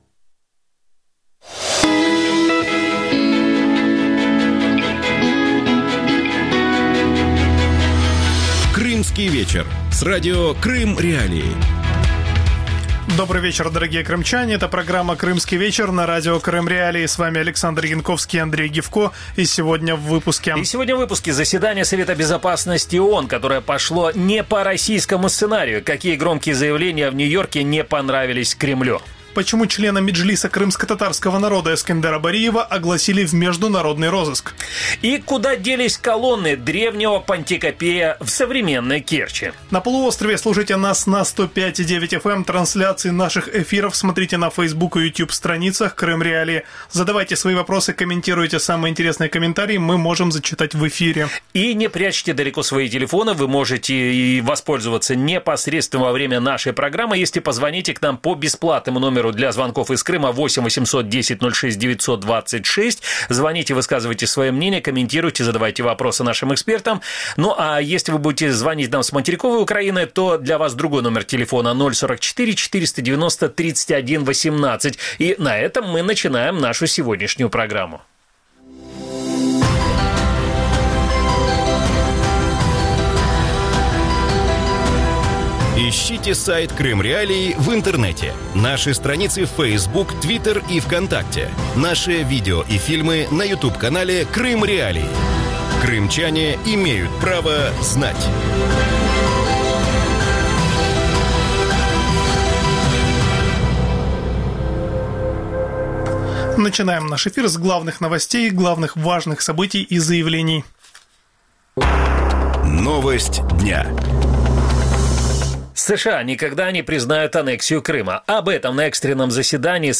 Как российская экономика реагирует или не реагирует на возможное введение новых санкций и что станет с ценами в Крыму? Гости эфира